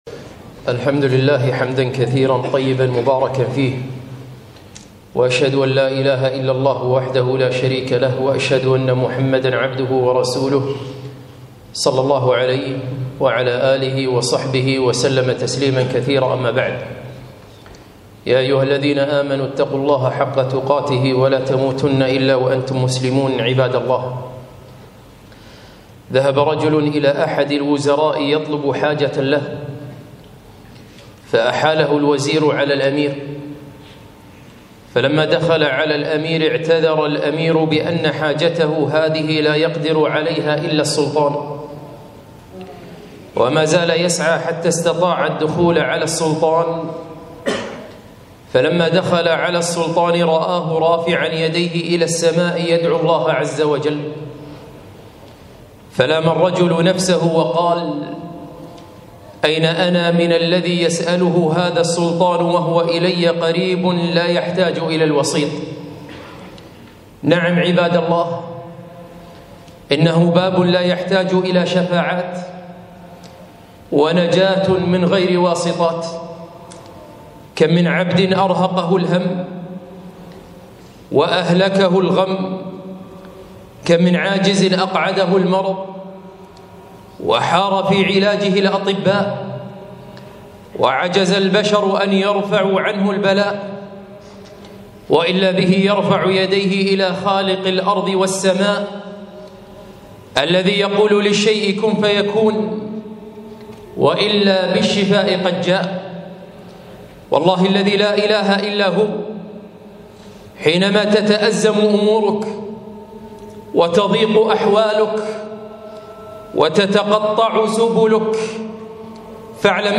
خطبة الدعاء وما أدراك ما الدعاء